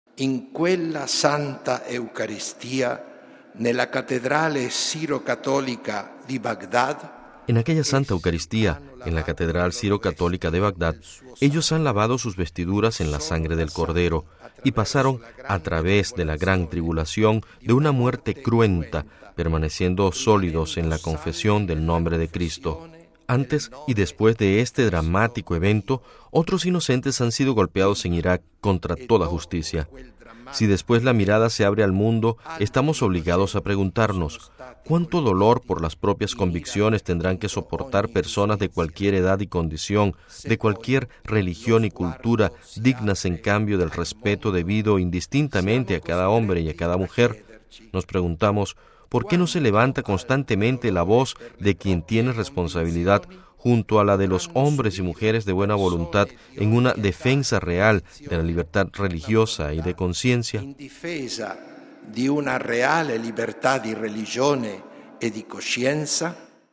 Este cuestionamiento fue pronunciado, esta tarde, en la basílica vaticana por el cardenal Leonardo Sandri, prefecto de la Congregación para las Iglesias Orientales, en su homilía durante la celebración eucarística en sufragio por los sacerdotes y fieles víctimas del atentado del pasado 31 de octubre en la catedral de Bagdad, Irak